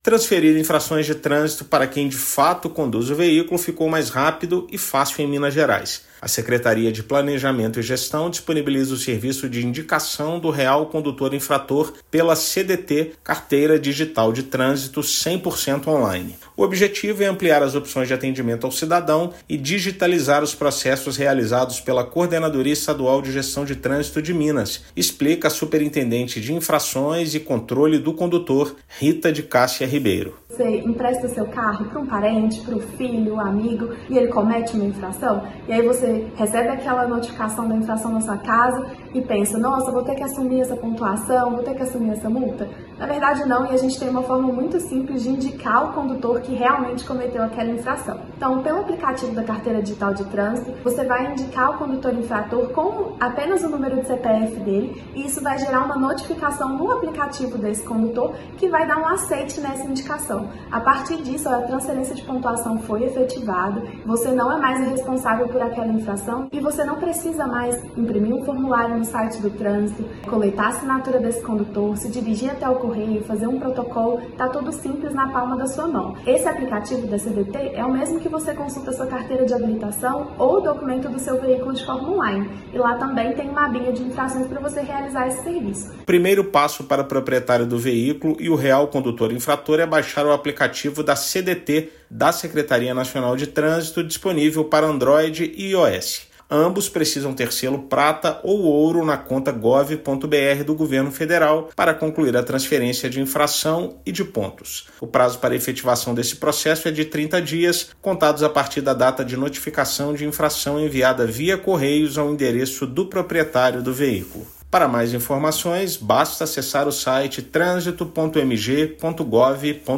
Funcionalidade está disponível na Carteira Digital de Trânsito e permite transferir os pontos para quem, de fato, cometeu a infração. Ouça matéria de rádio.